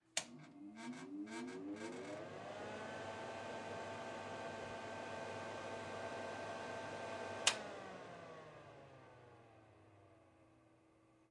压力锅
描述：使用Zoom H4n Pro录制厨房中正在运行的压力锅。
标签： 厨房 厨师 食品 现场录音 家居用品
声道立体声